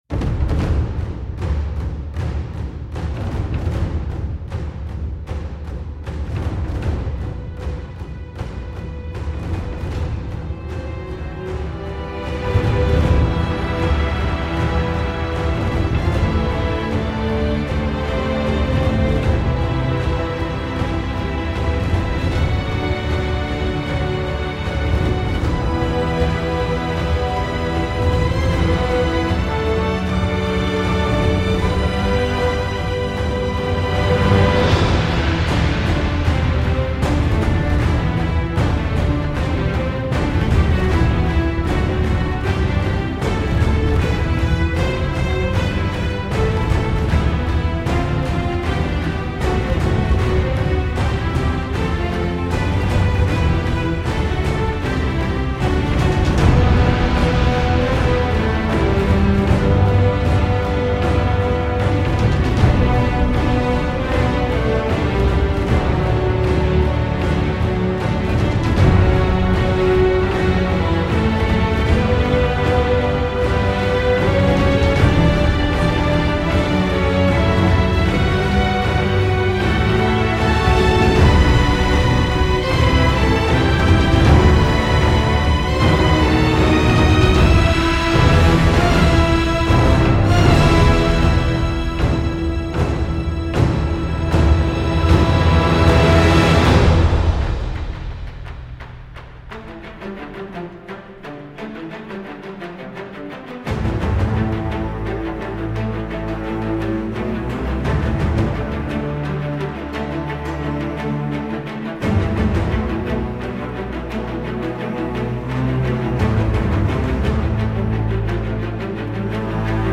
دسته : موسیقی متن فیلم